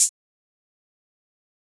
SouthSide Hi-Hat (2).wav